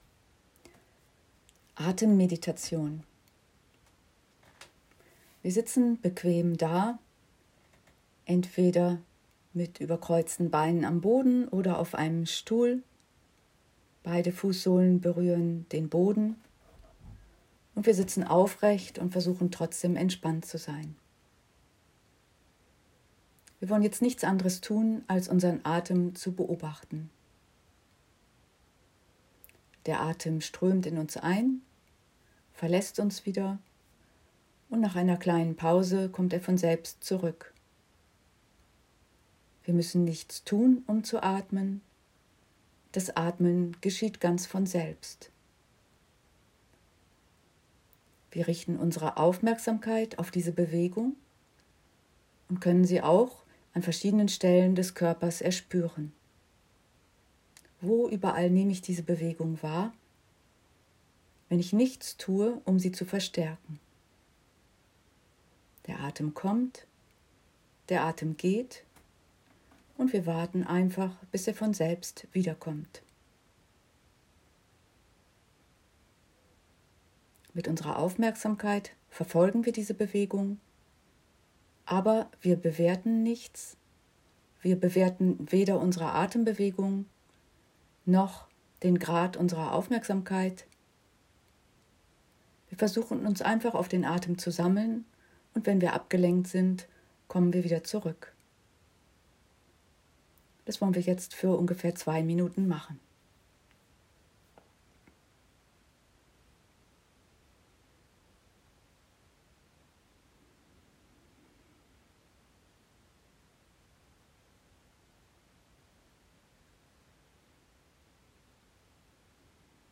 « Atemmeditation »